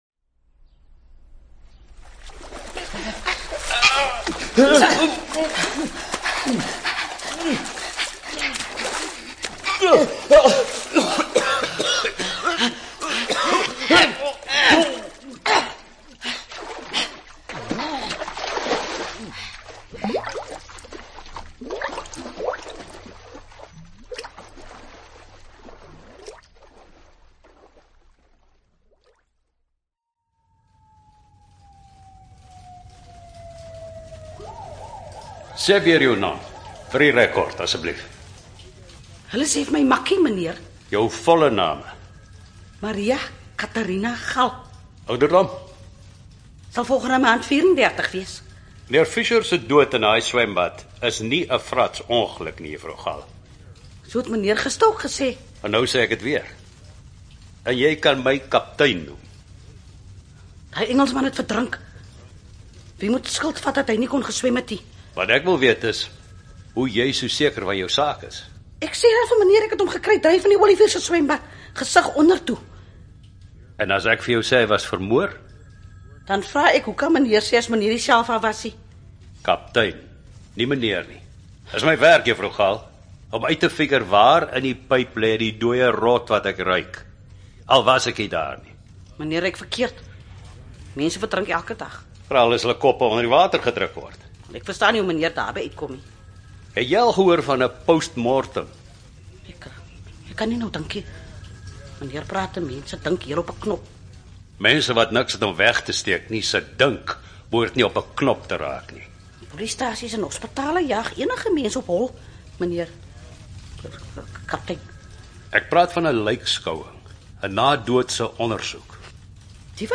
STORIE
Stemmingsmusiek is gekies om die atmosfeer van ‘n misdaaddrama te probeer weergee.